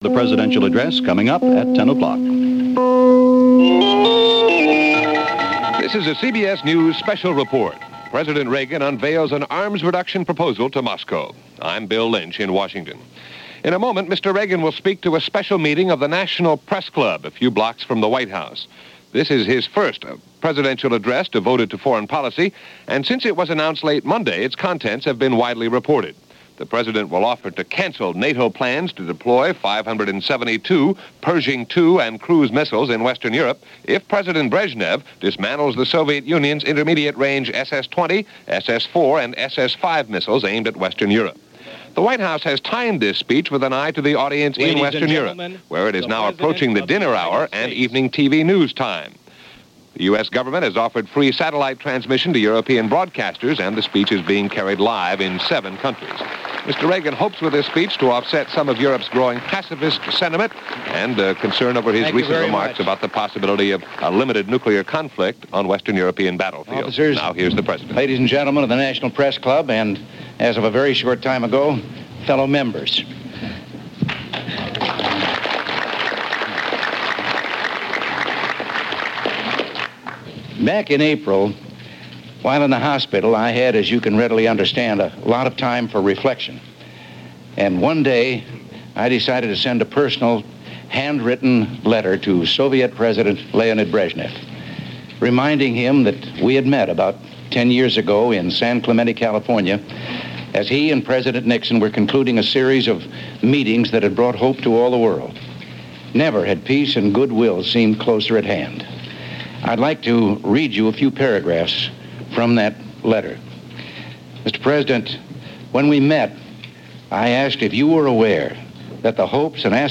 President Reagan and The Strategic Arms Reduction Talks - November 18, 1981 - Delivered at The National Press Club - Past Daily Reference Room.
President Reagan as he spoke to members of the National Press Club on November 18, 1981 for the purpose of disclosing an effort to diffuse a potentially volatile situation in Europe.